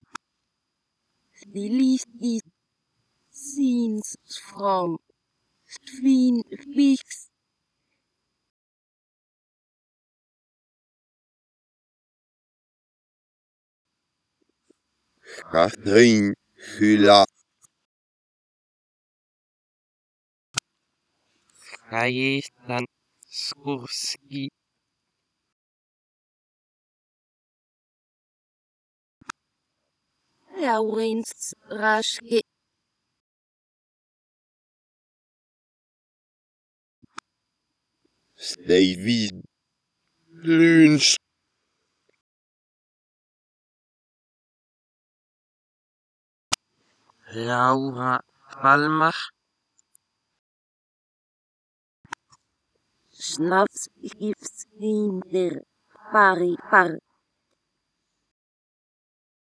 Start Musik Piano